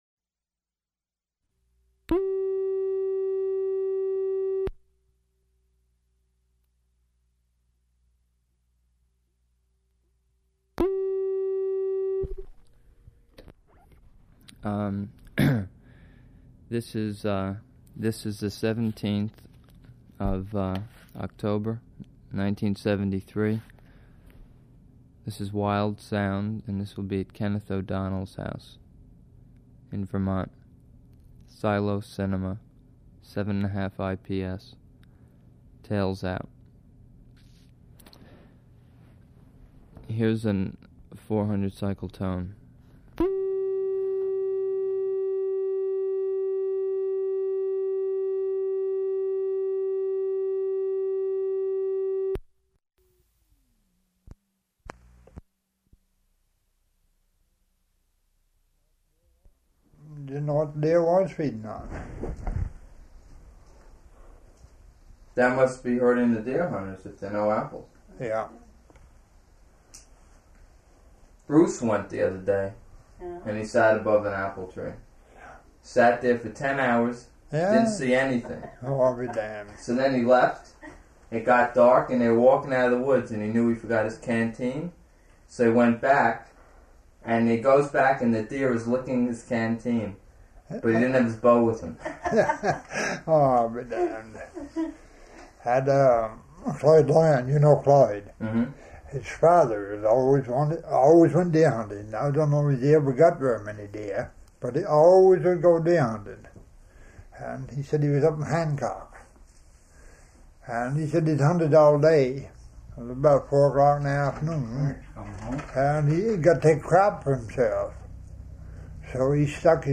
Interview
Format 1 sound tape reel (Scotch 3M 208 polyester) : analog ; 7 1/2 ips, full track, mono.